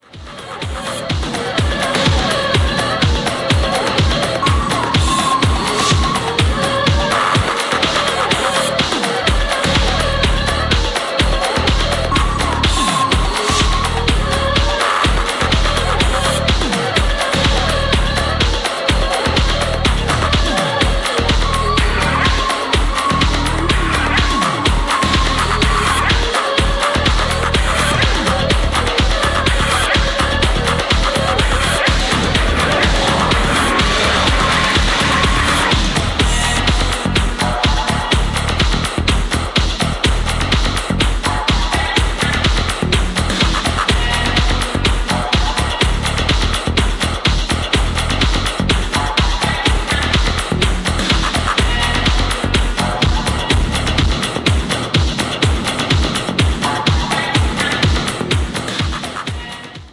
Industrial meets techno!